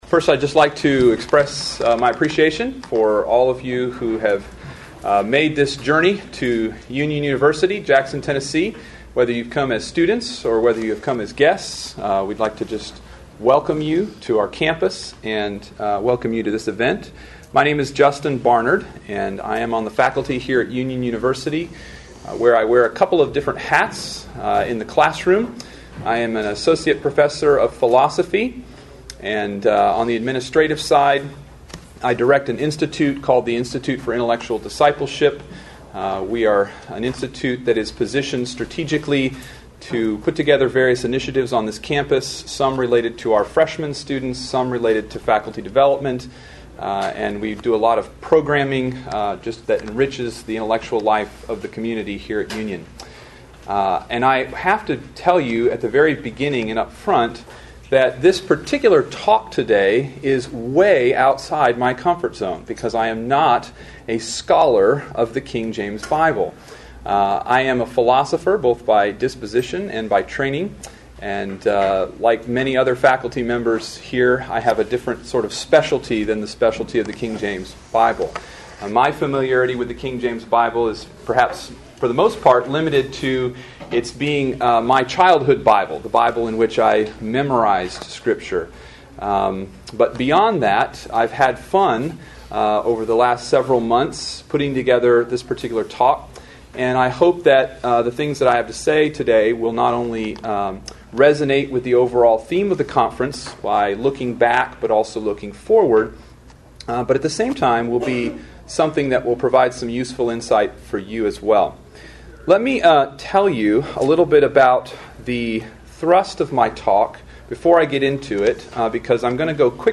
KJV400 Festival
Address: Human Nature and the Veneration of the KJV